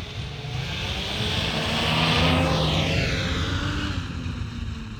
Subjective Noise Event Audio File (WAV)